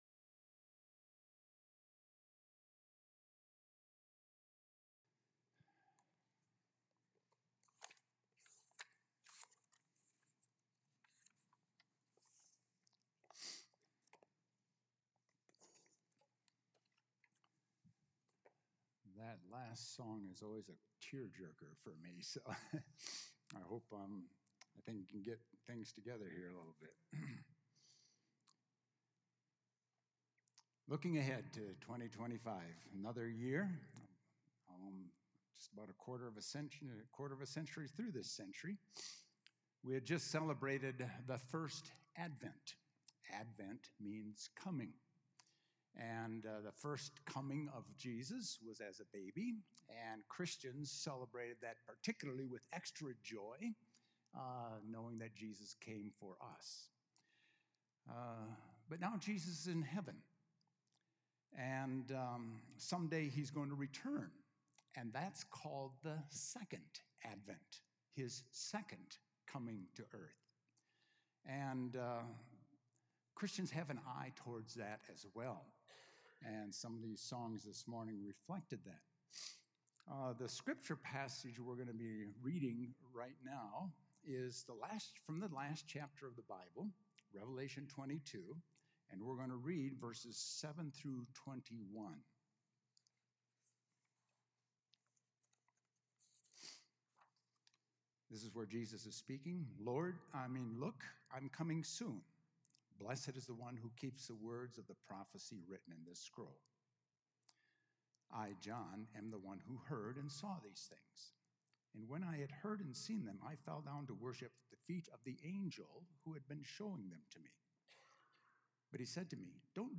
A message from the series "Jesus Return."